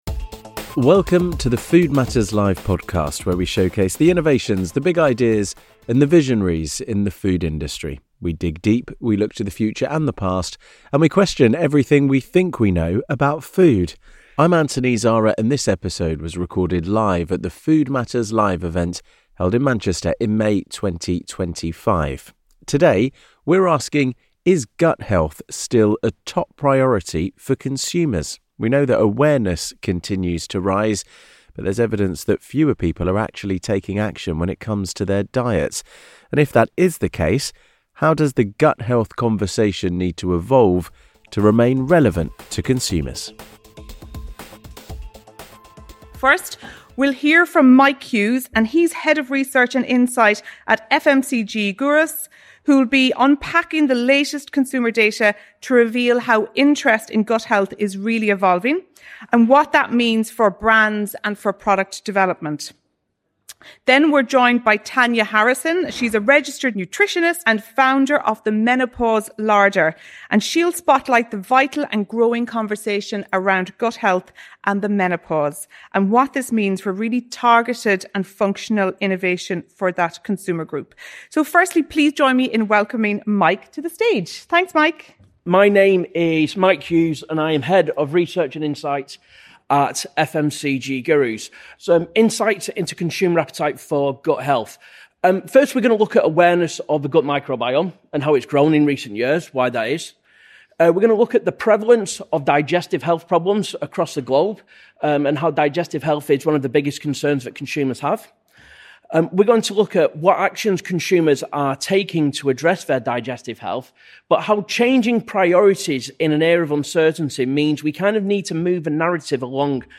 In this episode of the Food Matters Live podcast, recorded at our event in Manchester in May 2025, we explore how the gut health conversation needs to evolve beyond traditional disease management messaging.